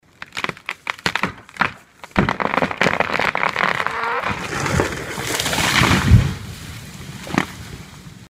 Звуки дерева